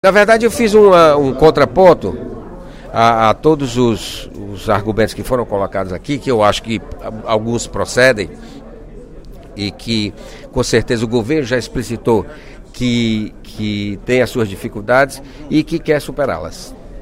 O deputado José Sarto (PSB), líder do Governo na Assembleia Legislativa, afirmou, durante o primeiro expediente desta terça-feira (16/07), que o governador Cid Gomes não está omisso em relação ao setor de segurança.